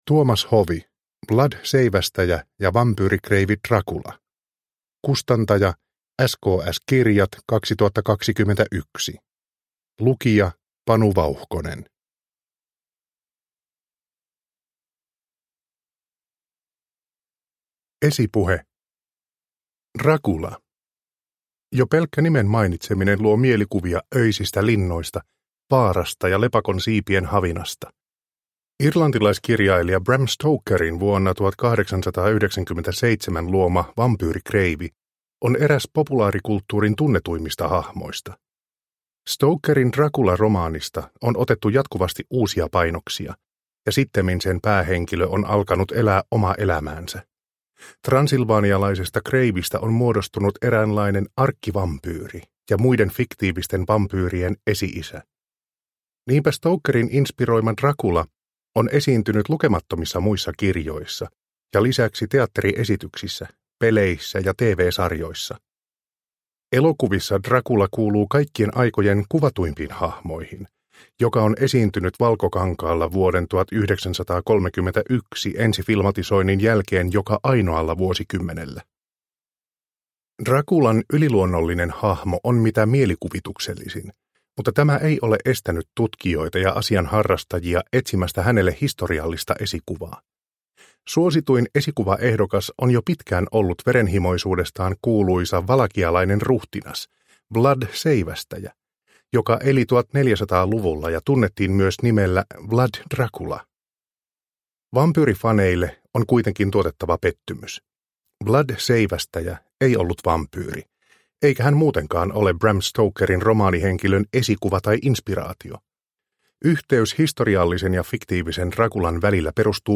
Vlad Seivästäjä ja vampyyrikreivi Dracula – Ljudbok – Laddas ner